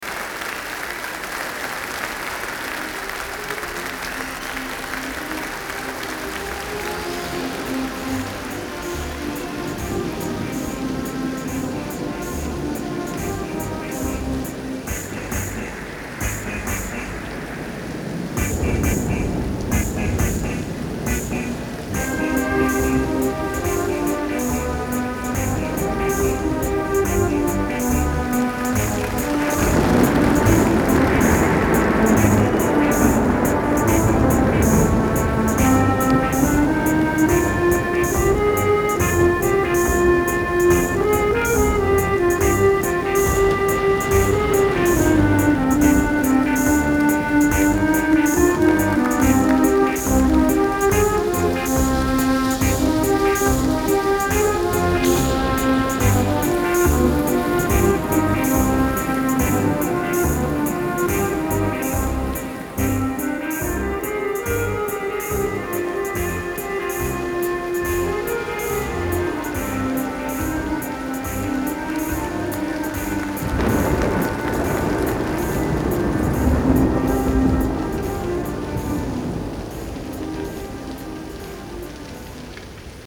Genre : Techno